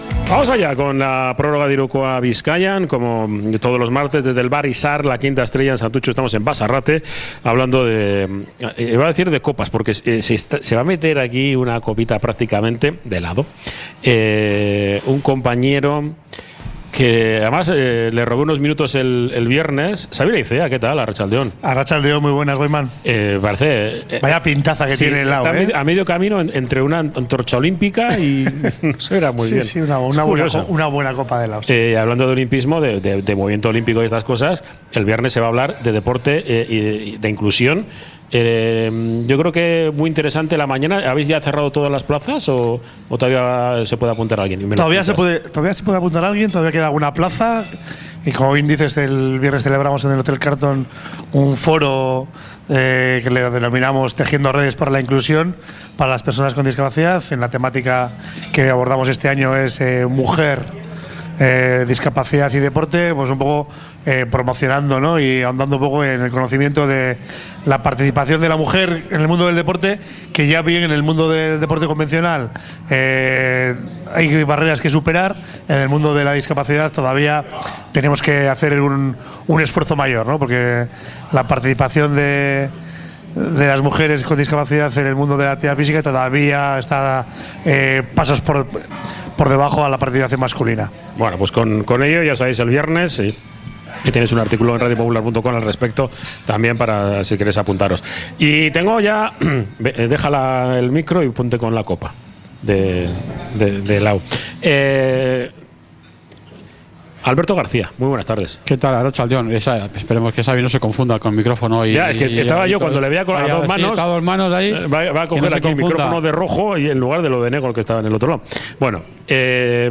Desde el Bar Izar la Quinta Estrella de Santutxu